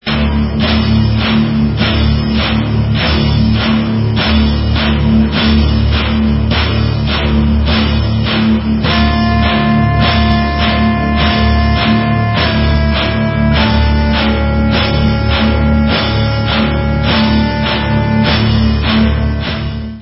Brass punk from finland